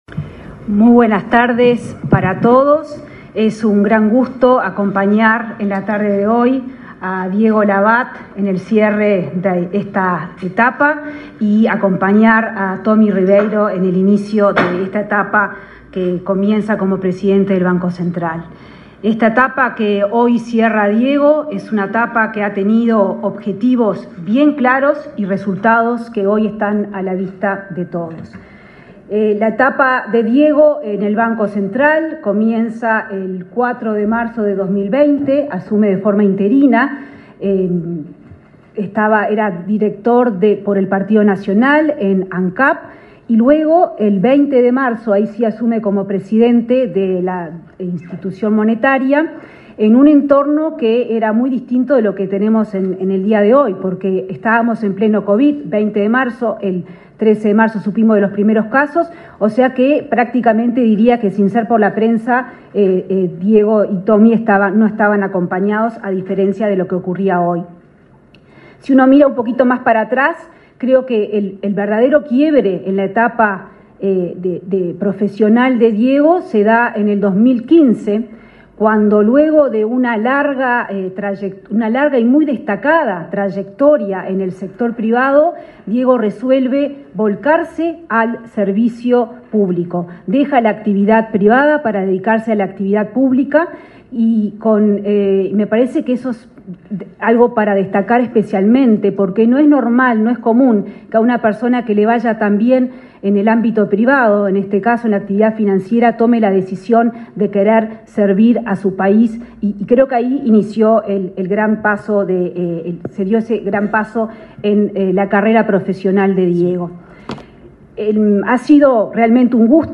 Palabra de autoridades en acto en el BCU
Palabra de autoridades en acto en el BCU 26/07/2024 Compartir Facebook X Copiar enlace WhatsApp LinkedIn La ministra de Economía, Azucena Arbeleche; el presidente saliente del Banco Central del Uruguay (BCU), Diego Labat, y el jerarca entrante, Washington Ribeiro, hicieron uso de la palabra en la asunción del nuevo presidente del directorio del organismo público.